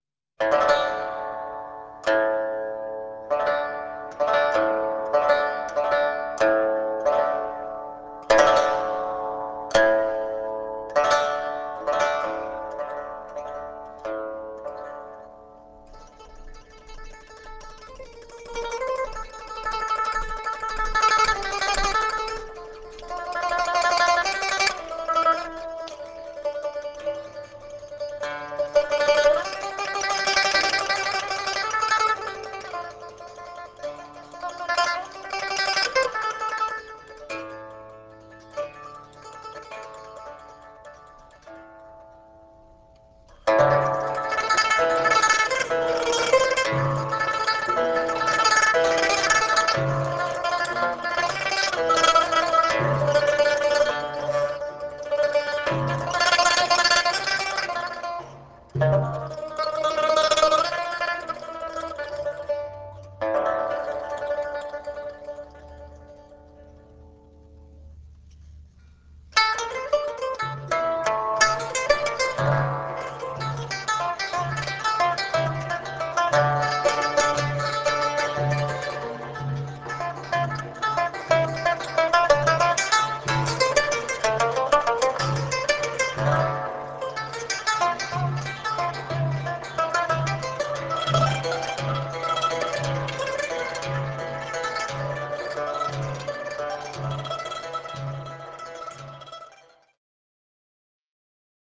deff, zarb, tar, dumbek